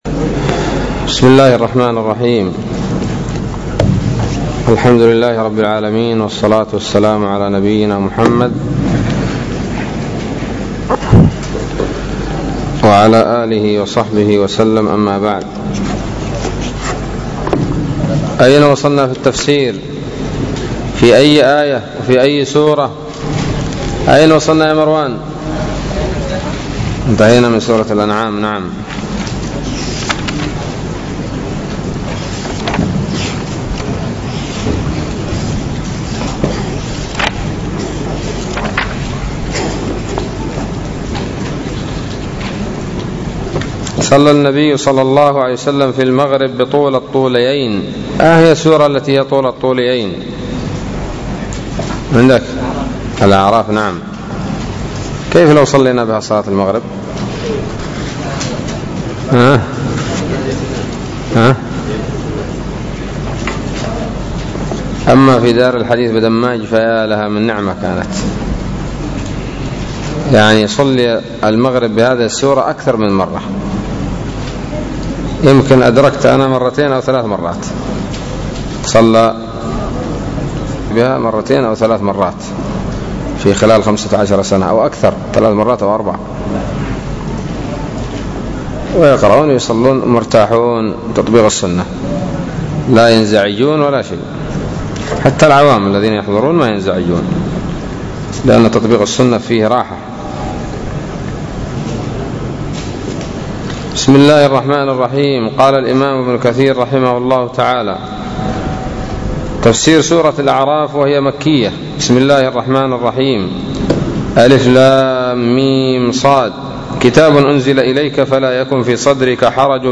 الدرس الأول من سورة الأعراف من تفسير ابن كثير رحمه الله تعالى